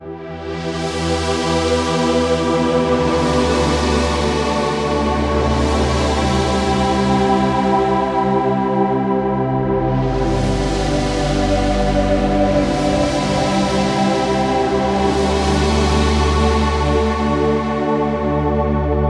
E D C G C D (对不起，101 BPM)
标签： 101 bpm Chill Out Loops Pad Loops 3.21 MB wav Key : Unknown
声道立体声